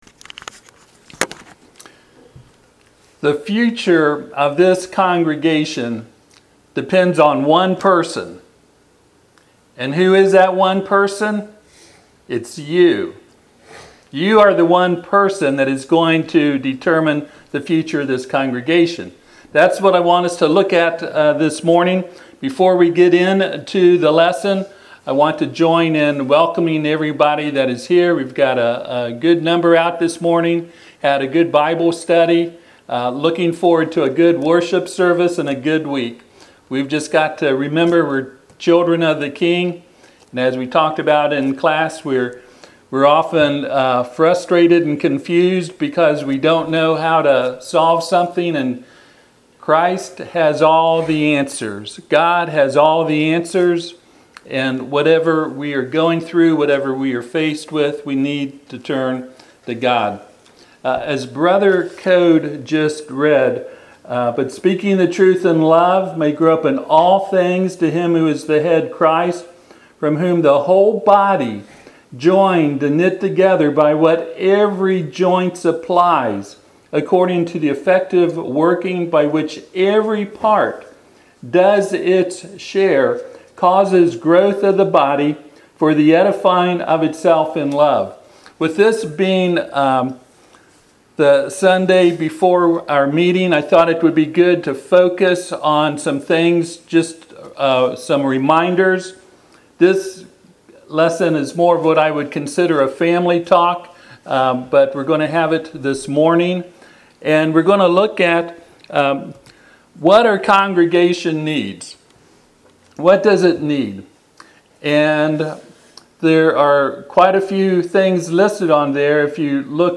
Passage: Ephesians 4:15-16 Service Type: Sunday AM « When The Load Is Too Heavy To Bear Nahum.